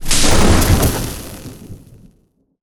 elec_lightning_magic_spell_04.wav